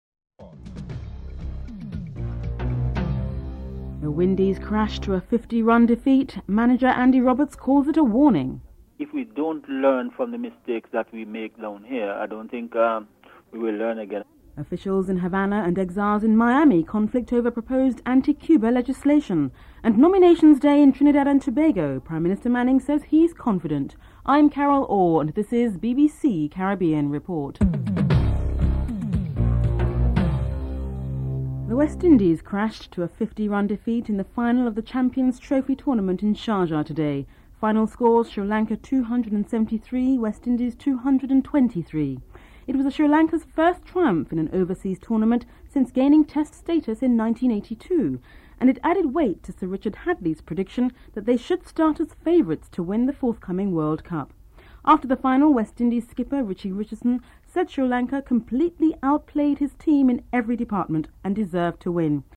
In Miami, Cuban exiles give their reaction to the anti-Cuba bill.